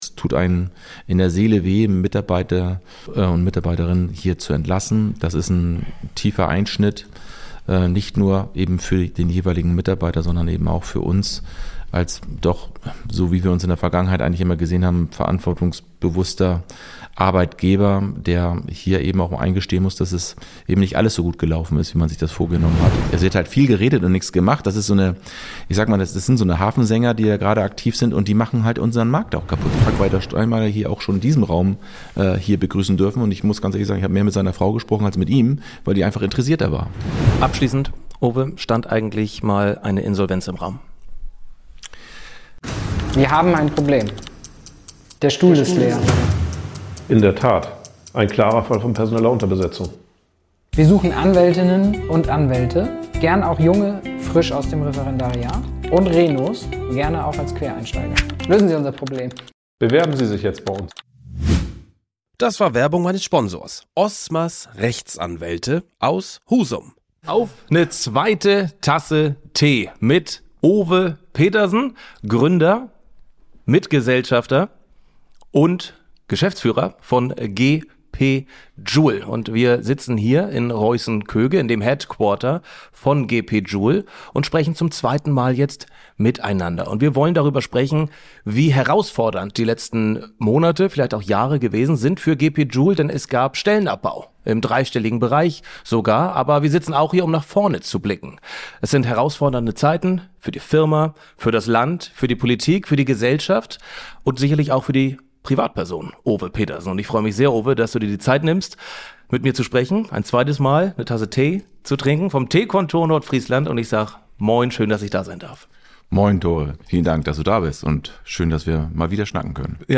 Ein intensives Gespräch über Energie, Führung, Zweifel, Haltung und die Frage, wie man weitermacht, wenn es ernst wird.